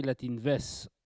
Localisation Saint-Jean-de-Monts
Langue Maraîchin
locutions vernaculaires